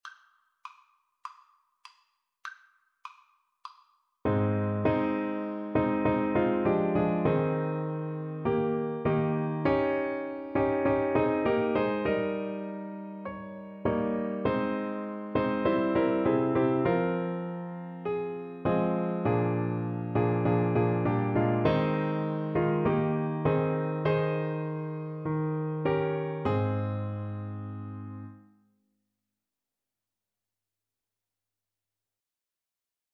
Play (or use space bar on your keyboard) Pause Music Playalong - Piano Accompaniment Playalong Band Accompaniment not yet available transpose reset tempo print settings full screen
Ab major (Sounding Pitch) (View more Ab major Music for Trombone )
4/4 (View more 4/4 Music)